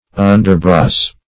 Search Result for " underbrace" : The Collaborative International Dictionary of English v.0.48: Underbrace \Un`der*brace\, v. t. To brace, fasten, or bind underneath or below.
underbrace.mp3